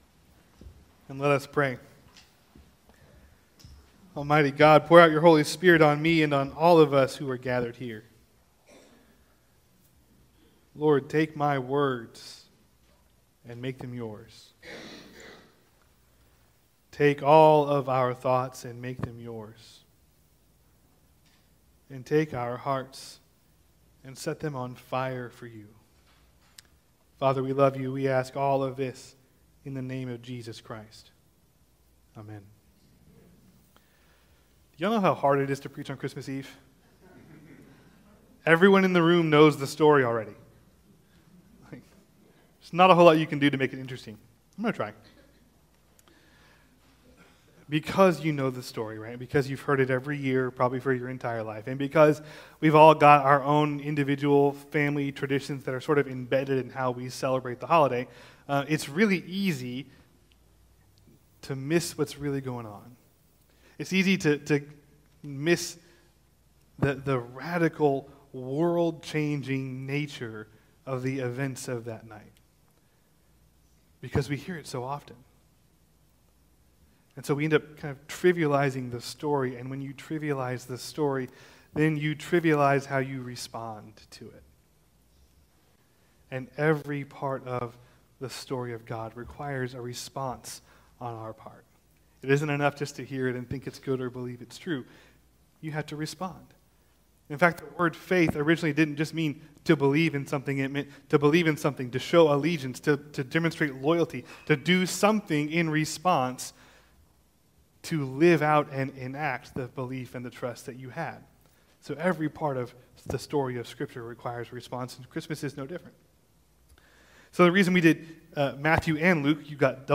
Sermons | Asbury Methodist Church
Christmas Eve